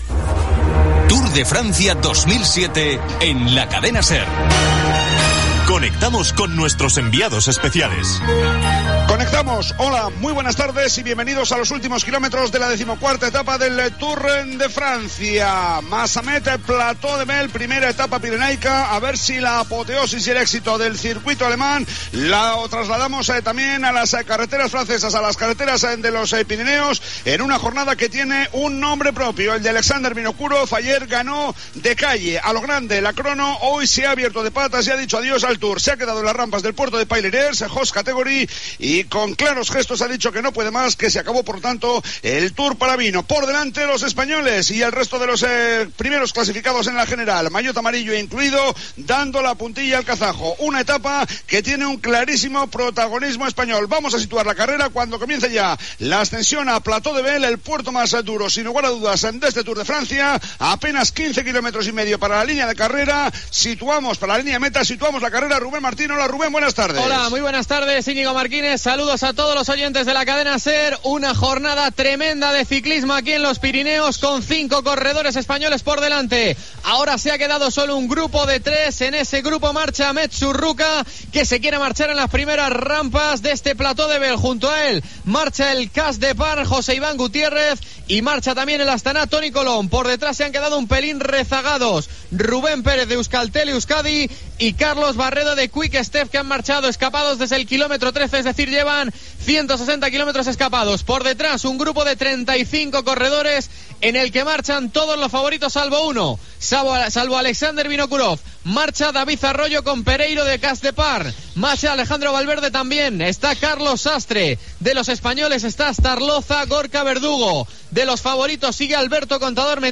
Narració dels 14 quilòmetres finals i de la victòria del ciclista Alberto Contador Gènere radiofònic Esportiu